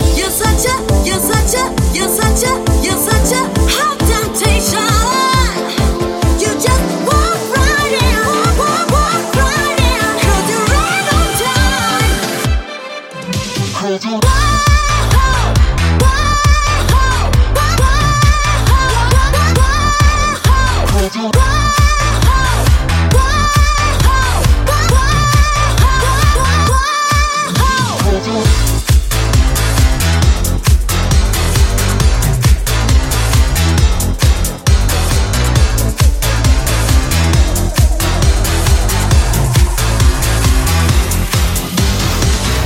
hits remixed
Genere: cover, edm, club. techno, successi, remix